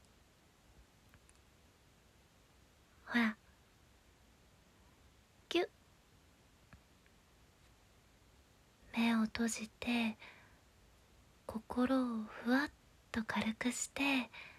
優しい彼女が、疲れたあなたを甘く囁き、寝かしつけるシチュエーションボイスです。
耳元で囁く甘い言葉、温かい吐息、優しいキスで、あなたの心を癒やします。